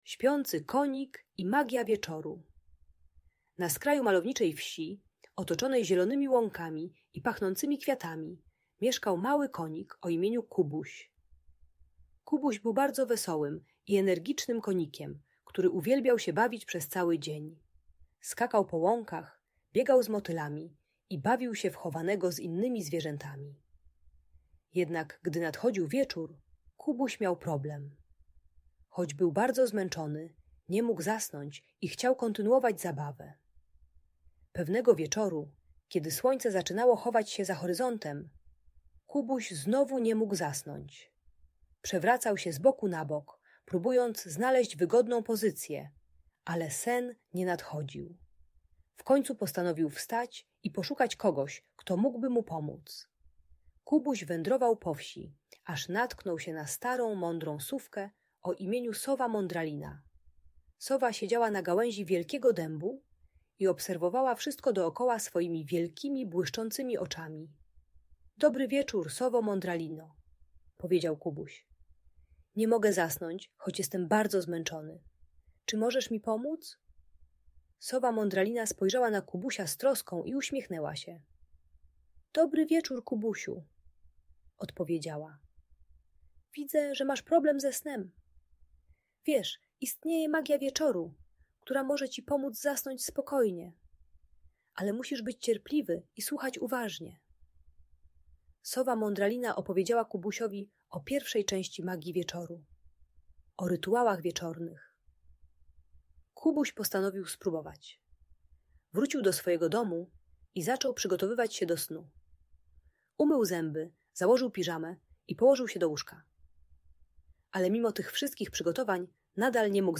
Śpiący Konik i Magia Wieczoru - Usypianie | Audiobajka
Ta bajka dla dziecka które nie może zasnąć uczy trzystopniowego rytuału wieczornego: przygotowania do snu, słuchania bajki na dobranoc oraz techniki głębokiego oddychania z wizualizacją spokojnej łąki. Darmowa audiobajka usypiająca.